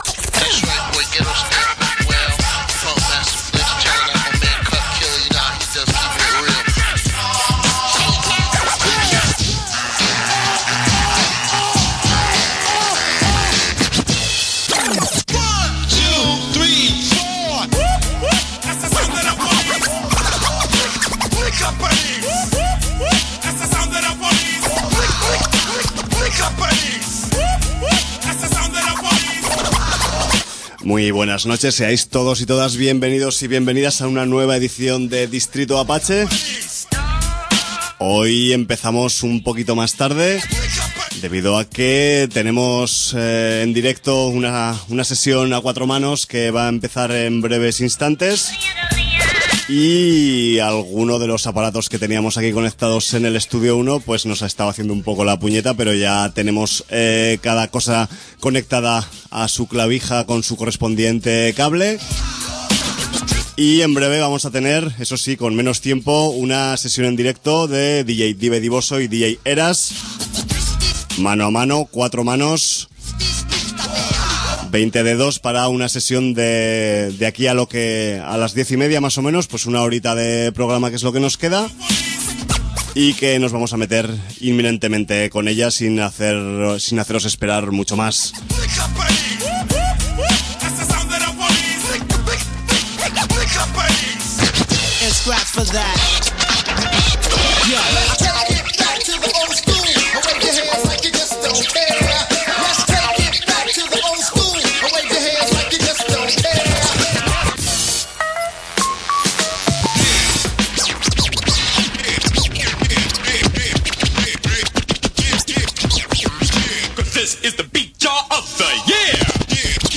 Sesión en riguroso directo y a cuatro manos desde Barcelona